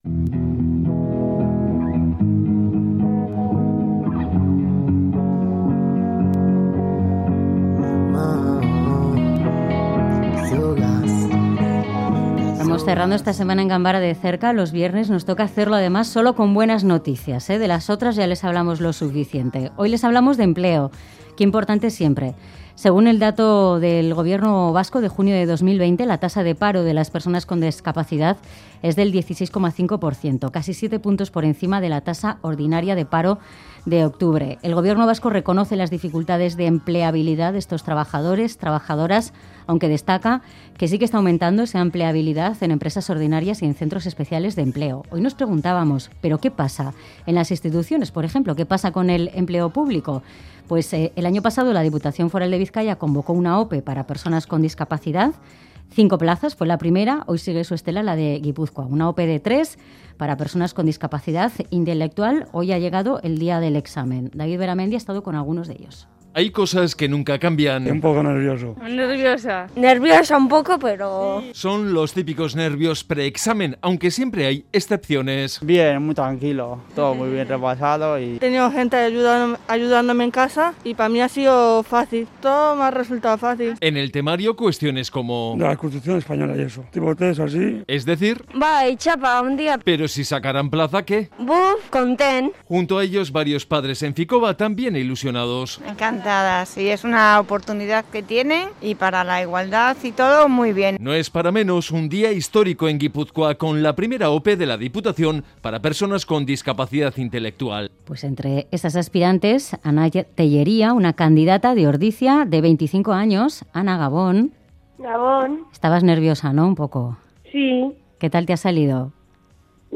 Audio: Charlamos con